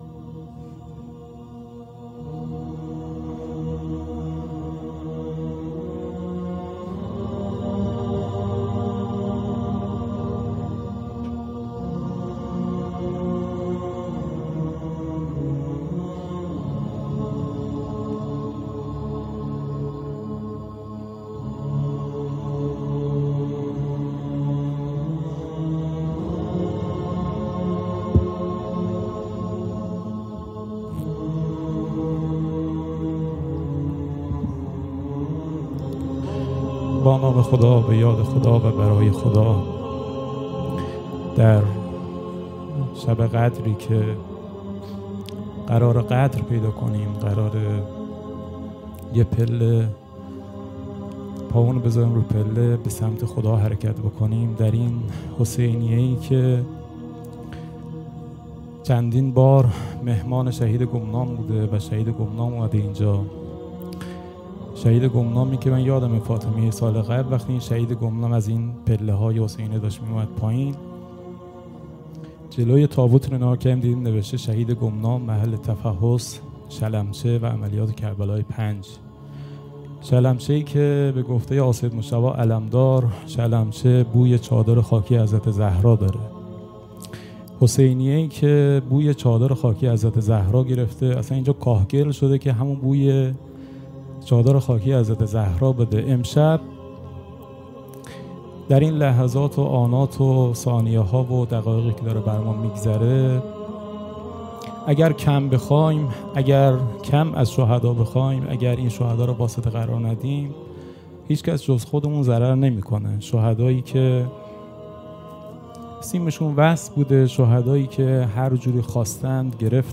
خیمه گاه - جوانان عاشورایی ساری - روایتگری شب قدر بیست و یکم رمضان